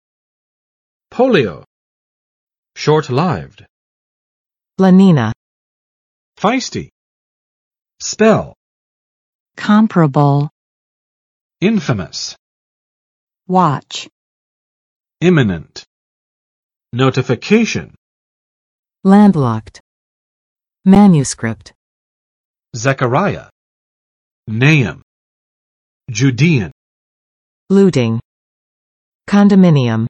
[ˋpolɪo]n.【医】小儿麻痹症；脊髓灰质炎